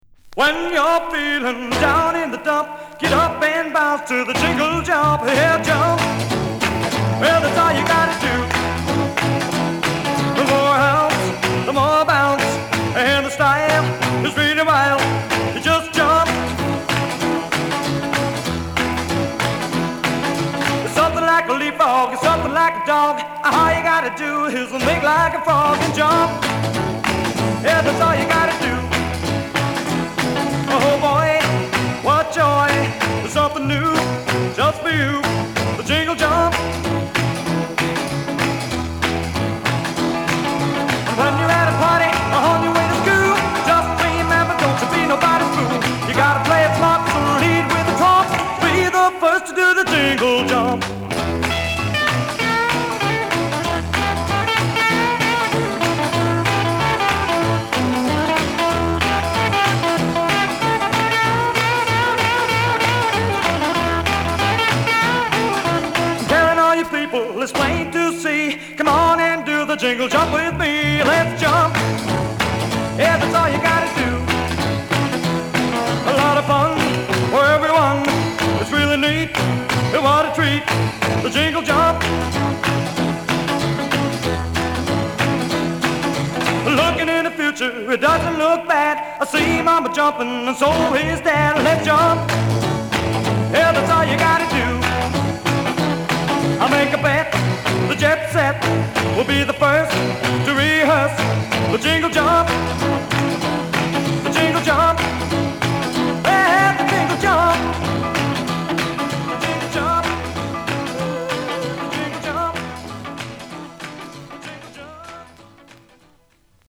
ウィスコンシン州ミルウォーキー出身のロックンロール・コンボ。